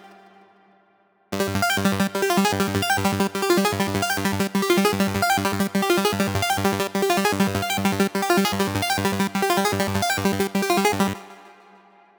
Hyper-Arp-G-200Bpm.wav